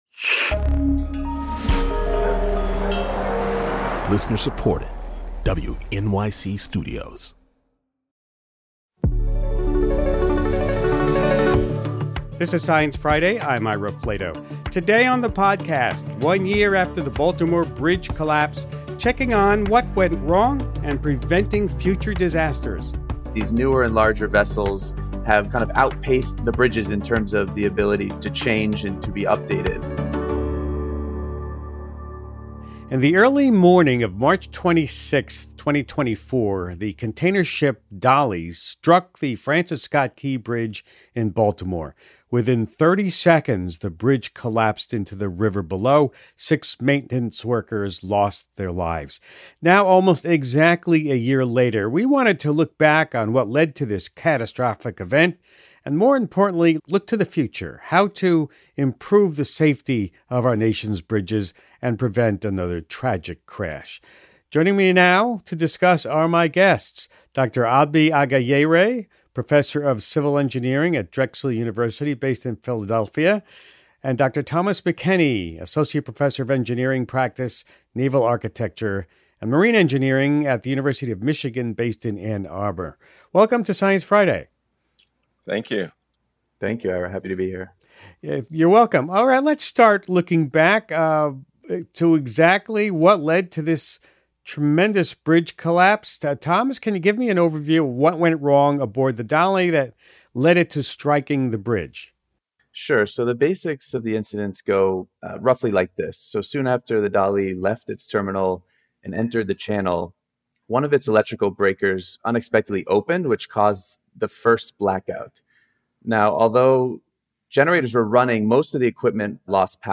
Host Ira Flatow is joined by